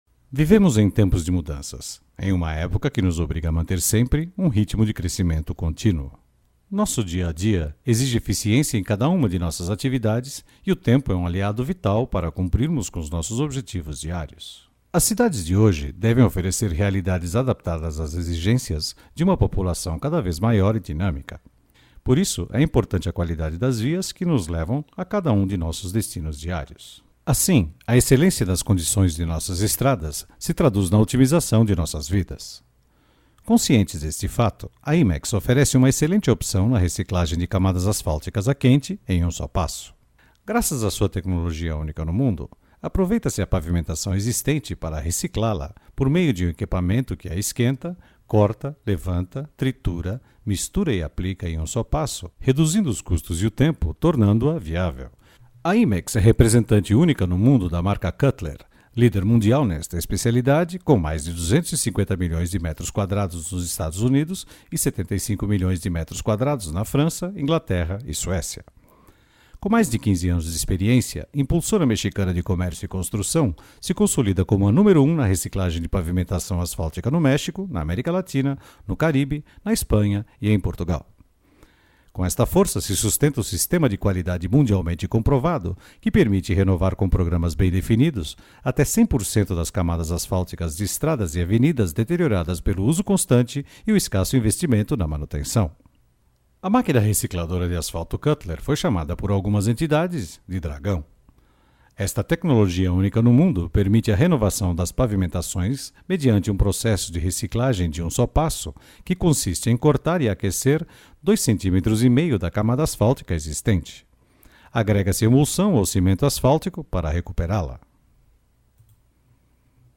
葡萄牙语翻译团队成员主要由中国籍和葡萄牙语国家的中葡母语译员组成，可以提供证件类翻译（例如，驾照翻译、出生证翻译、房产证翻译，学位证翻译，毕业证翻译、成绩单翻译、无犯罪记录翻译、营业执照翻译、结婚证翻译、离婚证翻译、户口本翻译、奖状翻译等）、公证书翻译、病历翻译、葡语视频翻译（听译）、葡语语音文件翻译（听译）、技术文件翻译、工程文件翻译、合同翻译、审计报告翻译等；葡萄牙语配音团队由葡萄牙语国家的葡萄牙语母语配音员组成，可以提供葡萄牙语专题配音、葡萄牙语广告配音、葡萄牙语教材配音、葡萄牙语电子读物配音、葡萄牙语产品资料配音、葡萄牙语宣传片配音、葡萄牙语彩铃配音等。
葡萄牙语样音试听下载